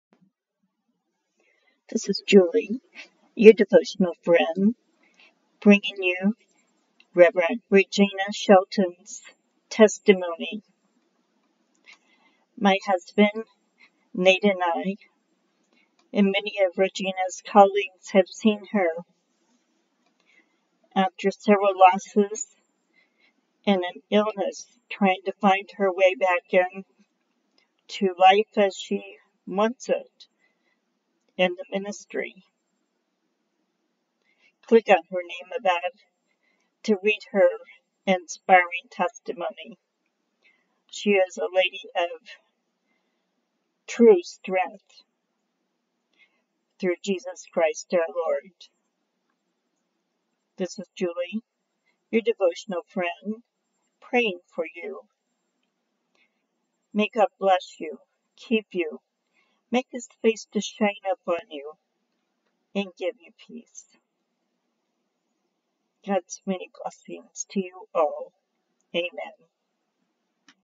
Testimony of Illness and Recovery